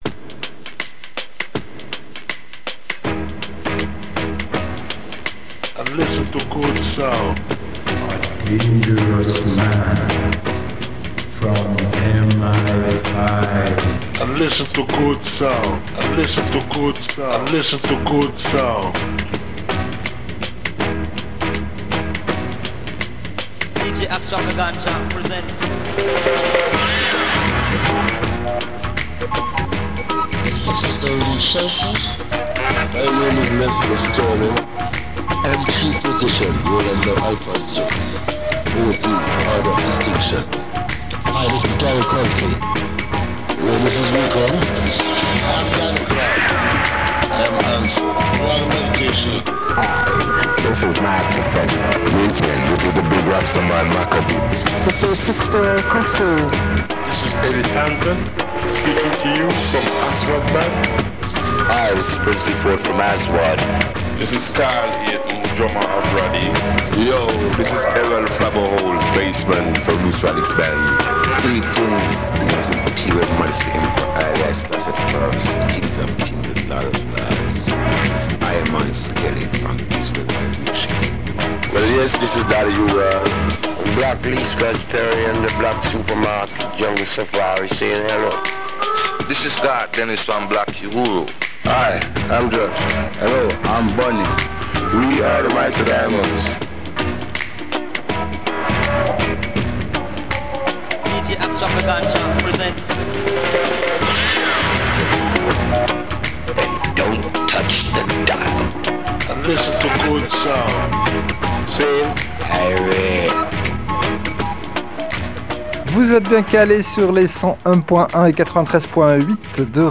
Black Super Market – radio show !
ska, afrobeat, dub, salsa, funk, mestizo, kompa, rumba, reggae, soul, cumbia, ragga, soca, merengue, Brésil, champeta, Balkans, latino rock…